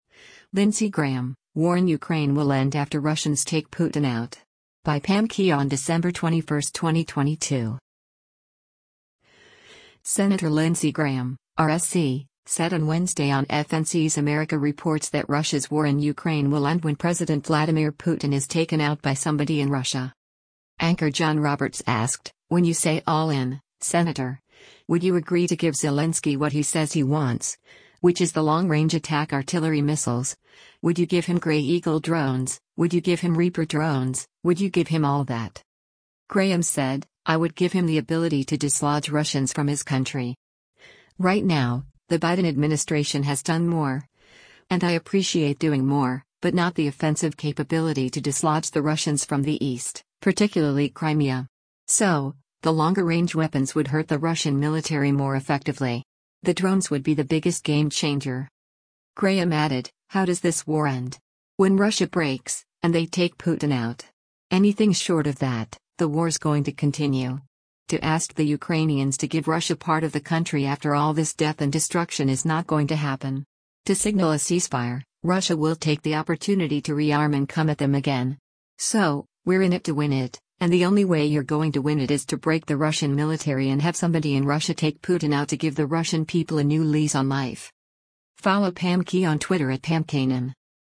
Senator Lindsey Graham (R-SC) said on Wednesday on FNC’s “America Reports” that Russia’s war in Ukraine will end when President Vladimir Putin is taken out by somebody in Russia.